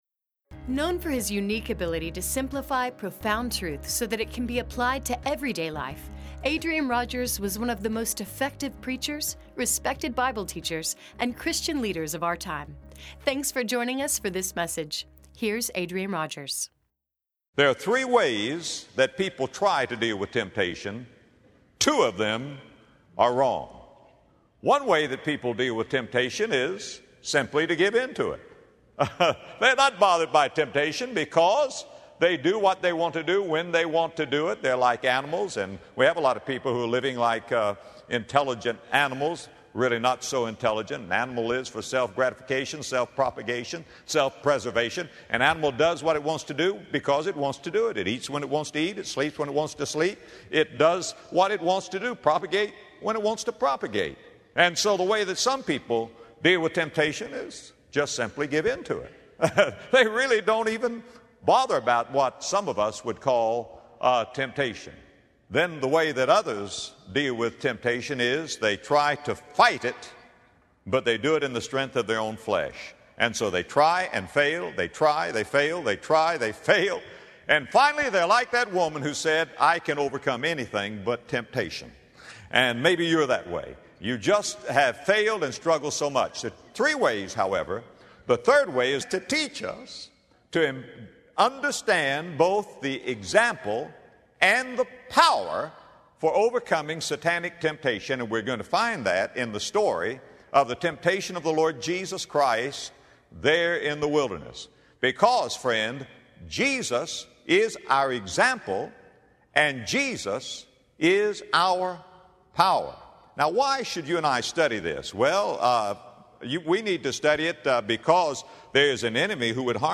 Temptation to sin can come to any person, at any point, wherever you may be. In this message, Adrian Rogers uses the example of Jesus in Luke 4:1-2 to show us how to handle temptations, so that we may be victorious over the battle of our hearts.